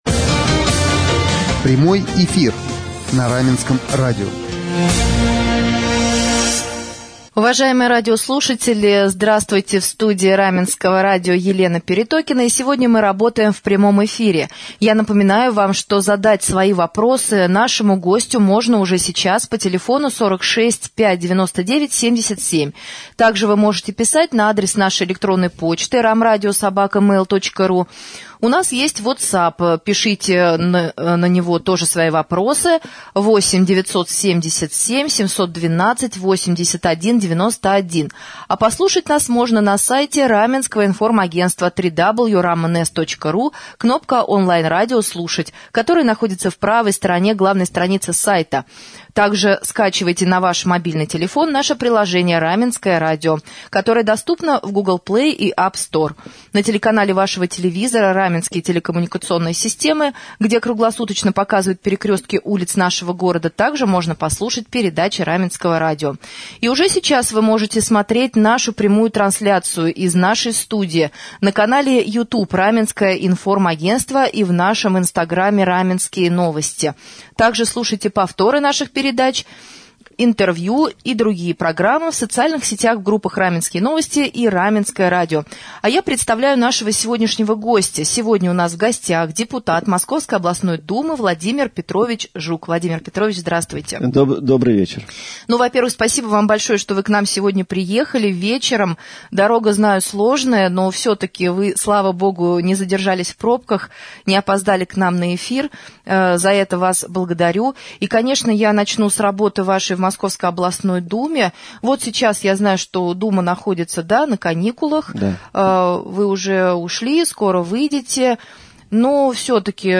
Депутат Московской областной Думы Владимир Петрович Жук стал гостем прямого эфира» на Раменском радио в четверг, 23 июля.